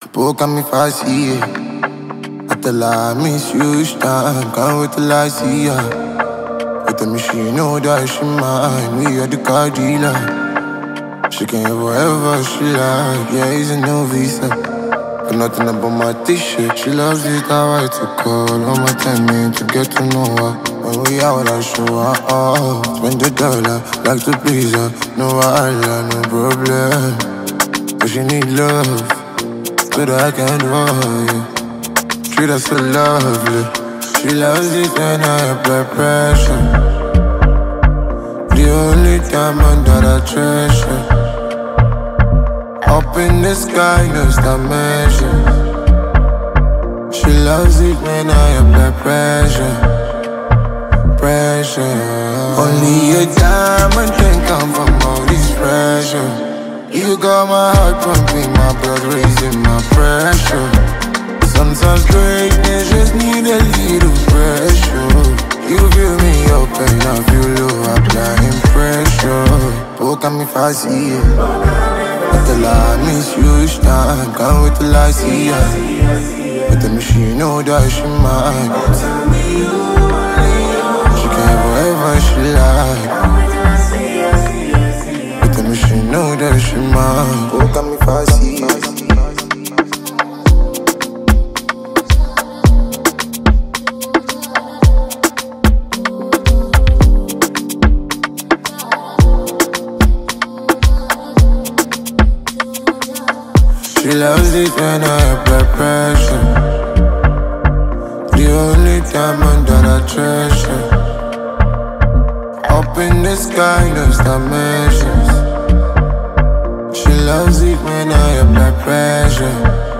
Street-pop
With his signature raw vocals and soulful delivery